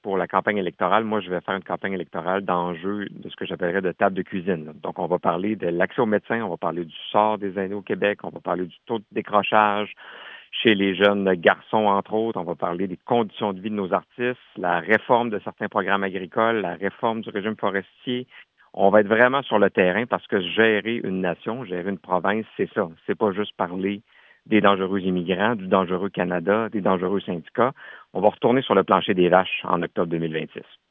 En entrevue avec le service des nouvelles de M105 ce matin, Charles Milliard pense déjà aux élections de l’automne.